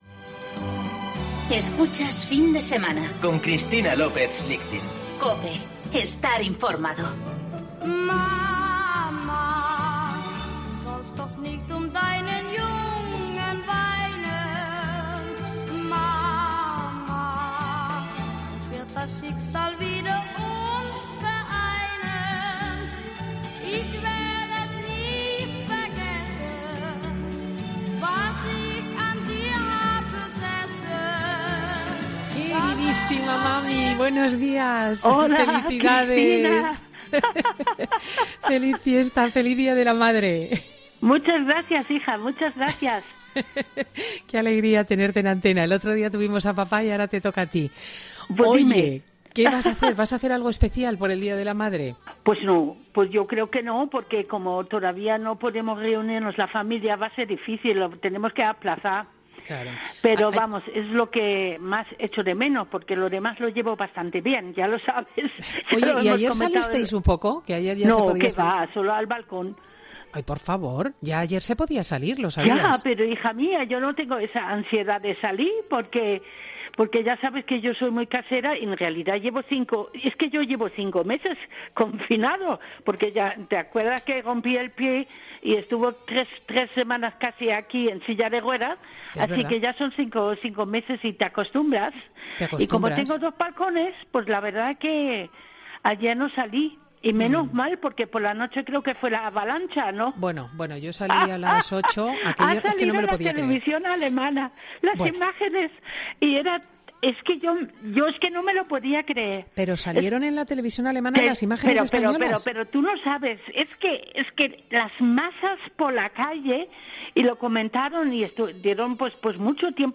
Ella es de Alemania y en su vida ha pasado por confinamientos mucho peores que este, algo que ha contado en Fin de Semana con su hija.